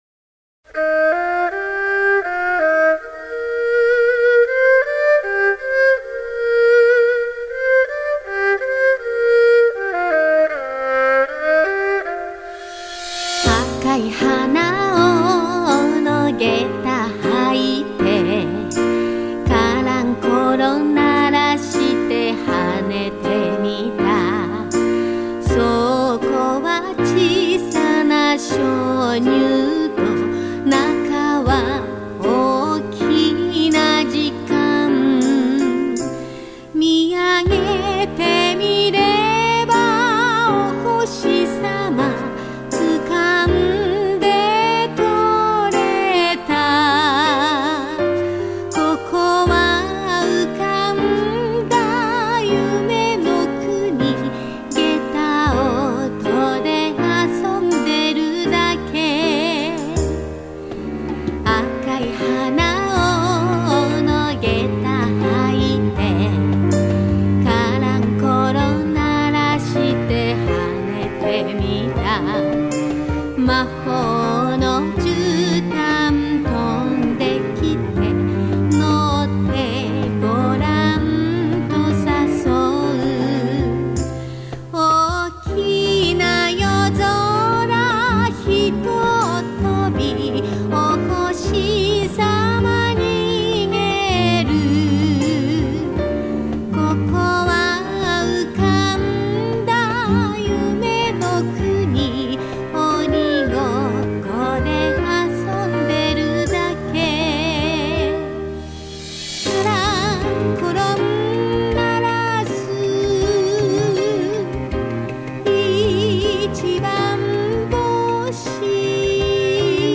その音楽とは「現代民族歌謡」といいます。